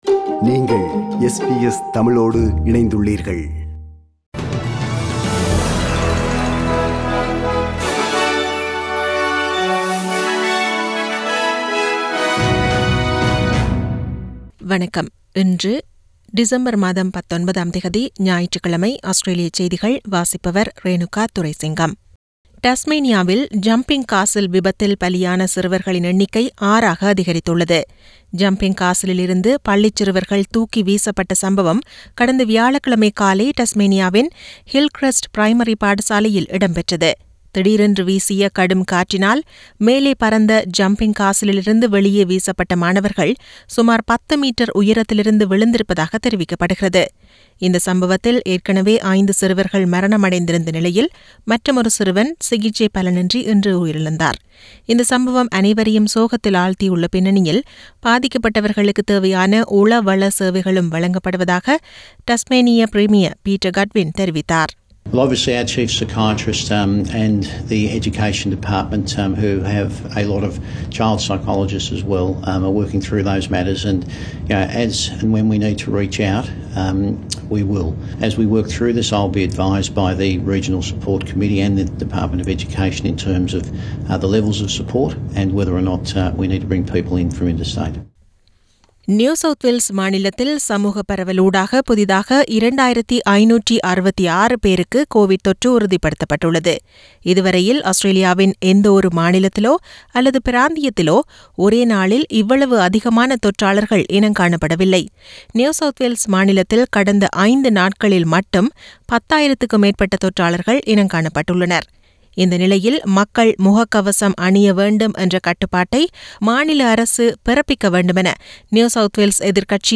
Australian news bulletin for Sunday 19 December 2021.